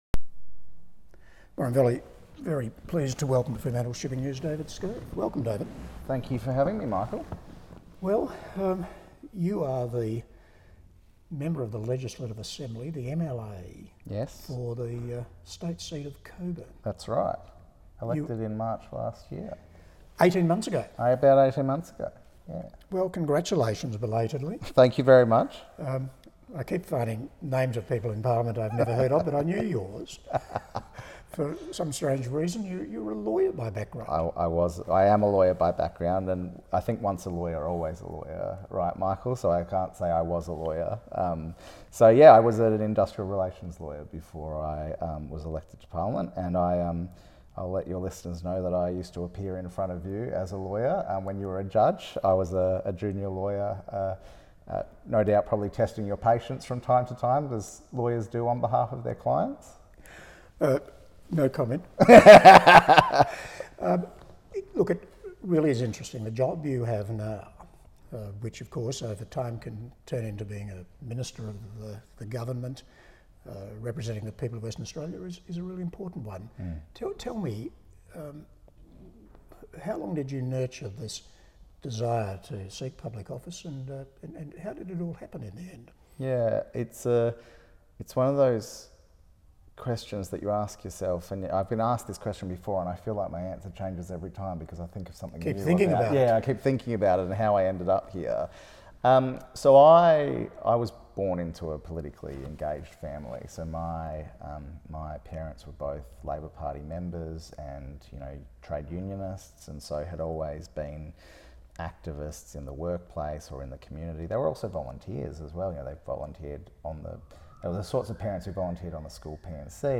Interview with David Scaife, MLA for Cockburn